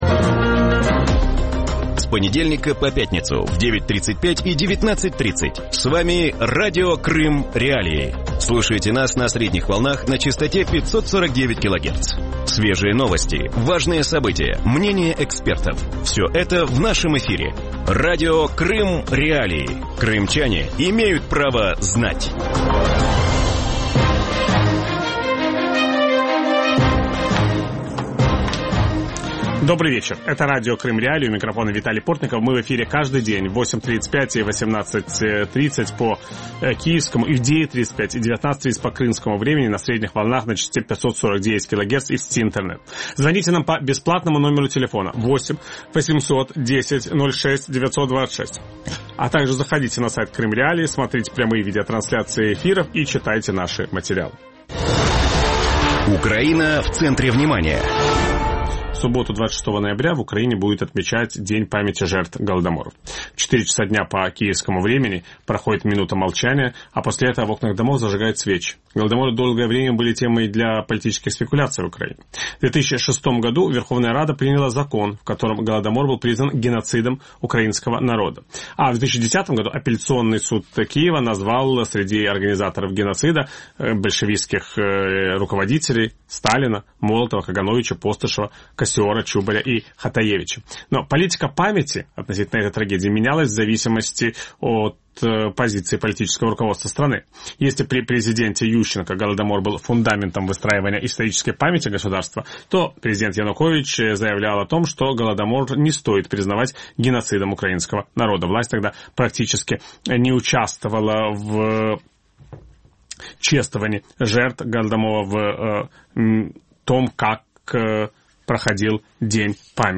У вечірньому ефірі Радіо Крим.Реалії говорять про День пам'яті жертв Голодомору в Україні. Як Голодомор змінив свого часу Україну і Крим, чому багато хто не може визнати злочини радянського режиму і якою має бути політика пам'яті цієї трагедії? На ці питання відповість історик, керівник Головного департаменту з питань гуманітарної політики Адміністрації Президента України Юрій Рубан. Ведучий: Віталій Портников.